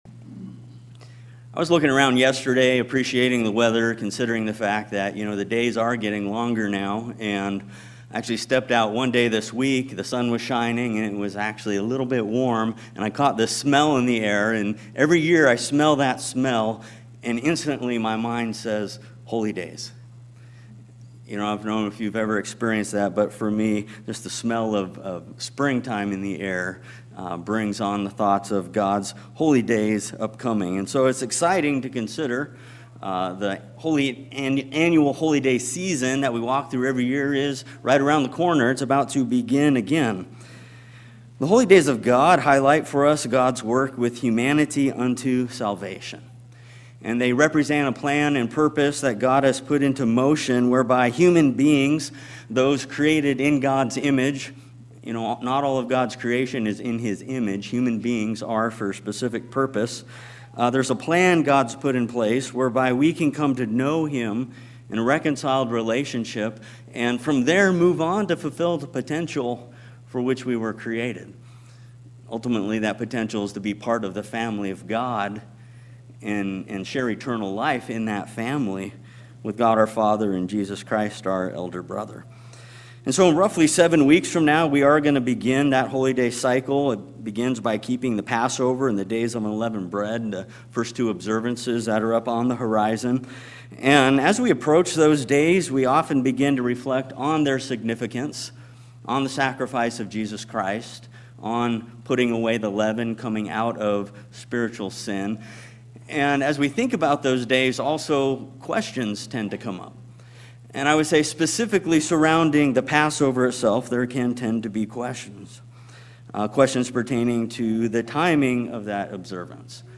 This sermon explores the timeline of the Exodus 12 Passover, as well as the final Passover Jesus kept with His disciples on that very same night in 31AD. Why we observe the Passover when we do, needs to be clear to us all.